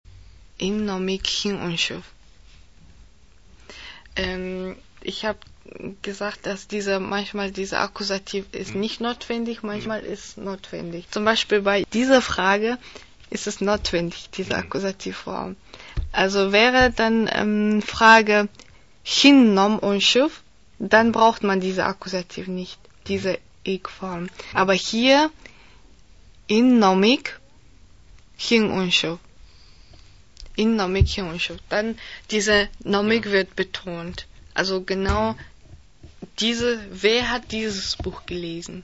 Originalton Lehrerin